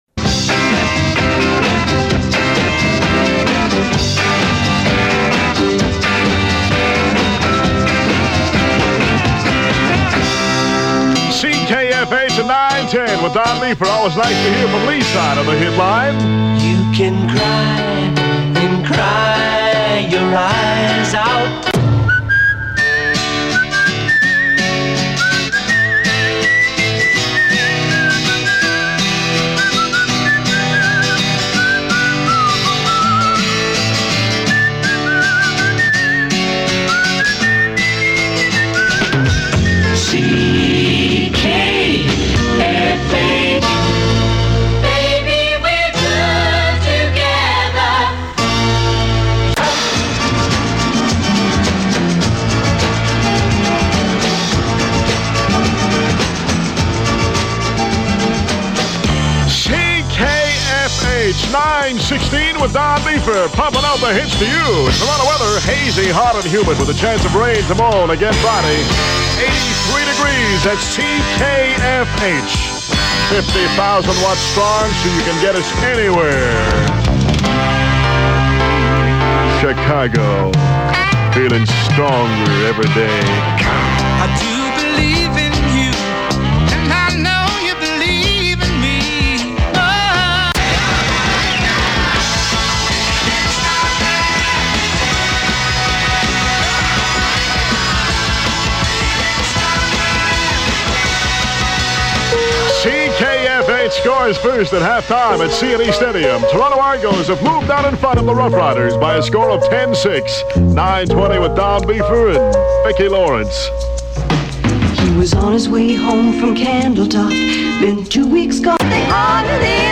" AIRCHECK OF THE WEEK E dition #1358 Week of August 3